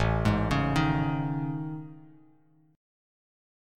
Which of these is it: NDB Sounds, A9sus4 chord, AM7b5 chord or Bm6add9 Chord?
AM7b5 chord